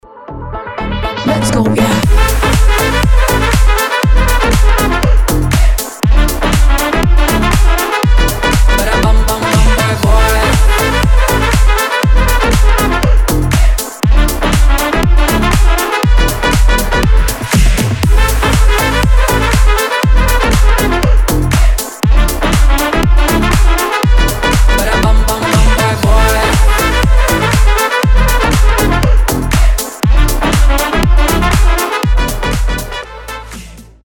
позитивные
зажигательные
веселые
цыганские
house